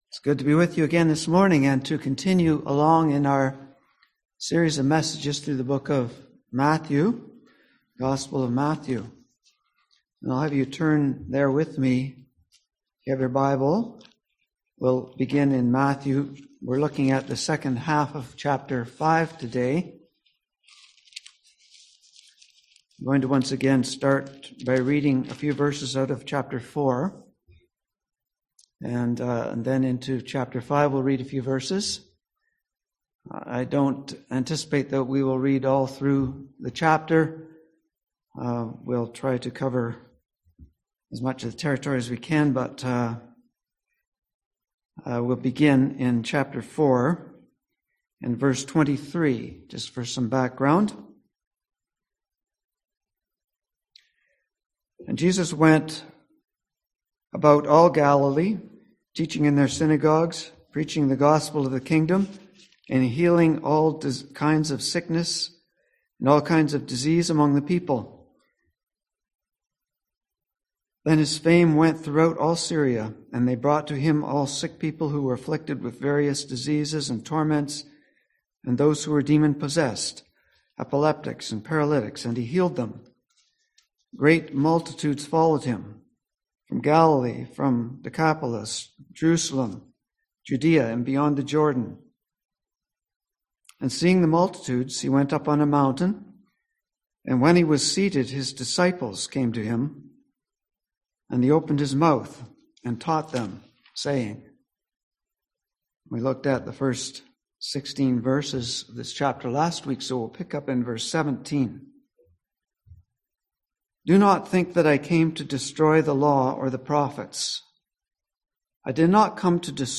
Passage: Matthew 5:17-48 Service Type: Sunday AM